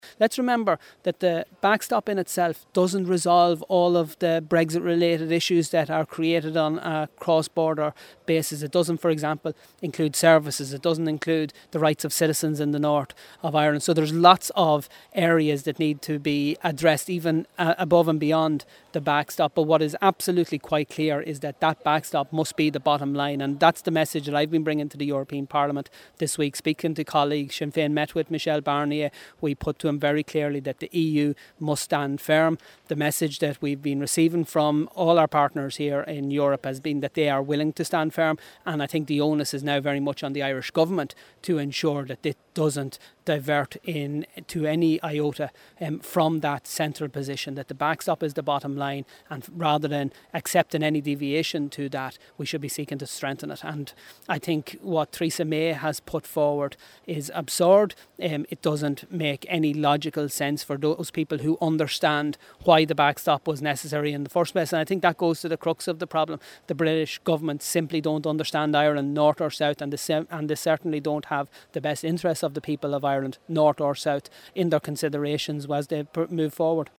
That’s the view of Midlands North West MEP Matt Carthy who was speaking in the European Parliament in Strasbourg.